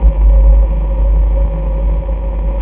描述：令人毛骨悚然的环境声音，低音循环悬挂，从旧收音机录制并过滤/清洁等
Tag: 低音 背景 无线电 循环 蠕动 快感 suspence